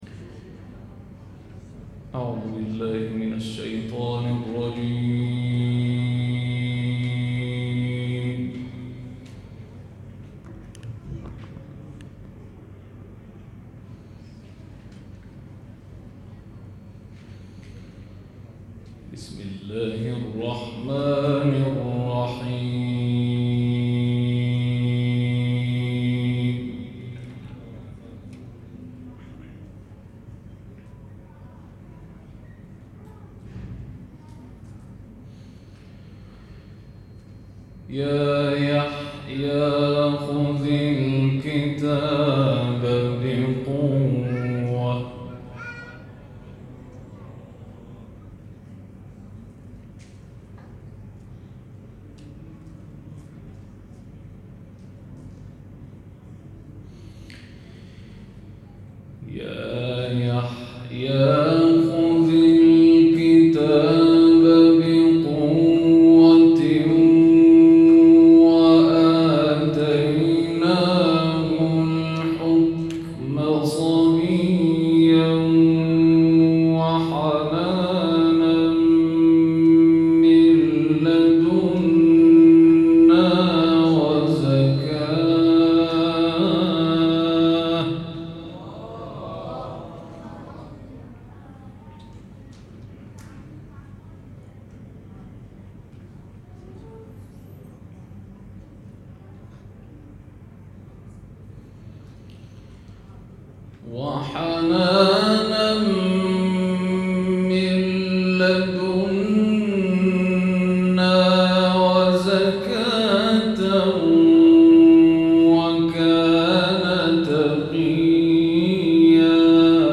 گروه شبکه اجتماعی ــ قاری بین‌المللی کشور، شب گذشته در محفل نبطیة در کشور لبنان، به تلاوت کلام الله مجید پرداخت.